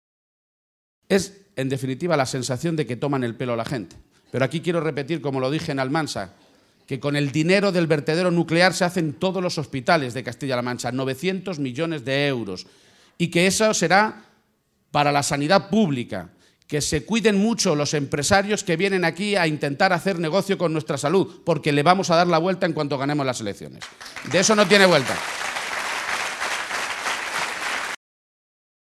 García-Page se pronunciaba de esta manera esta mañana en Azuqueca de Henares, primera de las tres localidades de la provincia de Guadalajara que ha visitado junto al secretario general del PSOE, Pedro Sánchez.